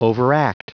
Prononciation du mot overact en anglais (fichier audio)
Prononciation du mot : overact